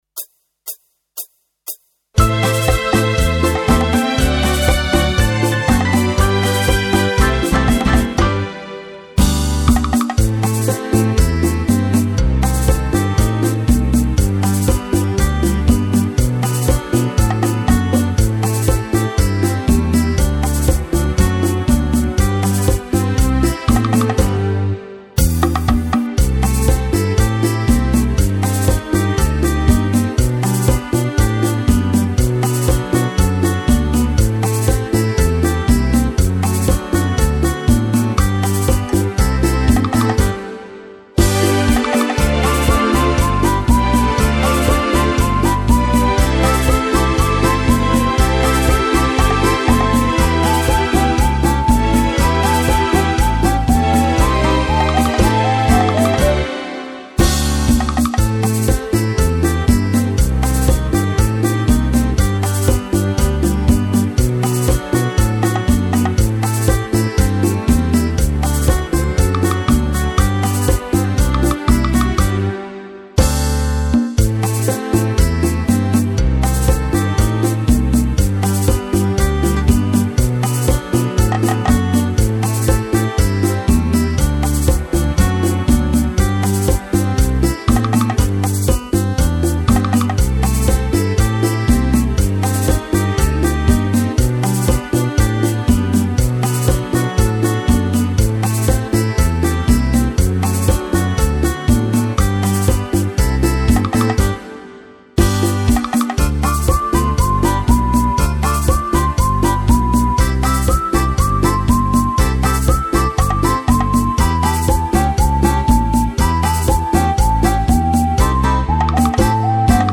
Bachata. Orchestra